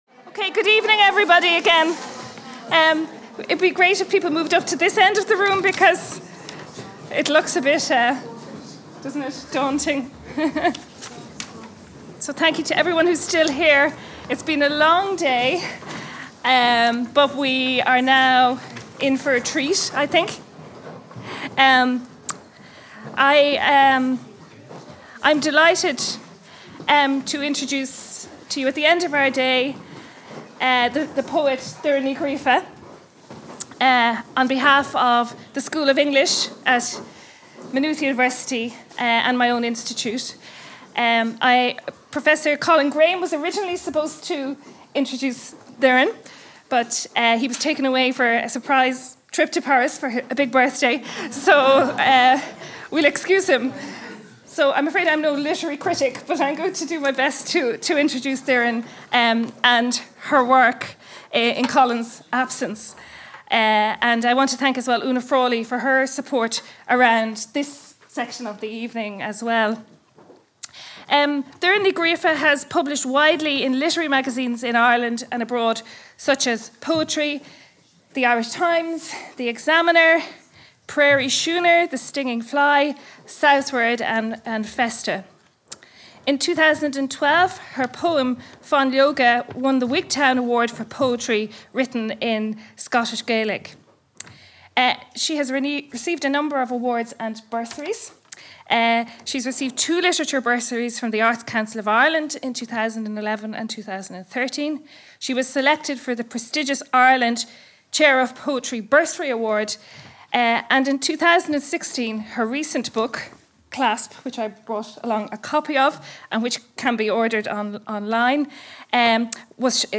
Audio of Recital at Women and the Irish Revolution 1917-23 by Doireann Ní Ghríofa | Maynooth University
At this conference in the RIA in Dublin, Doireann Ní Ghríofa was invited to recite some of her poetry which both honors the Centenaries of the Proclamation and speaks to the women of Ireland.
Doireann reading